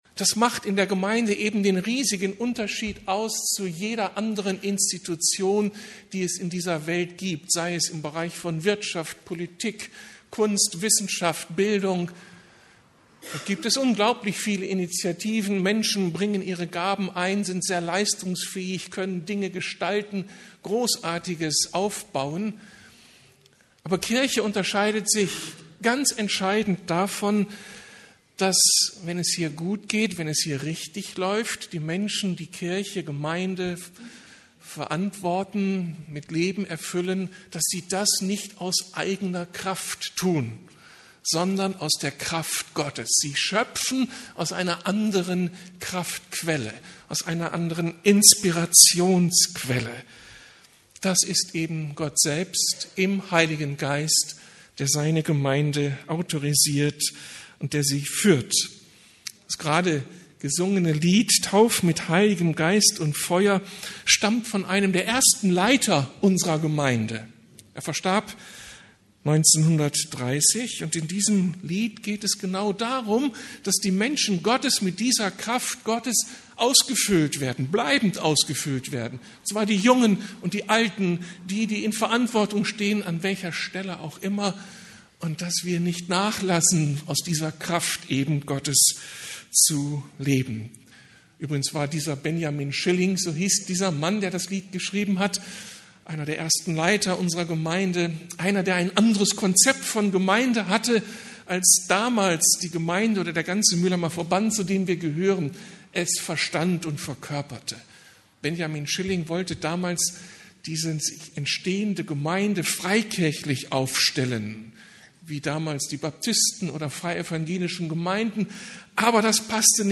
Unser Erbe neu entdecken ~ Predigten der LUKAS GEMEINDE Podcast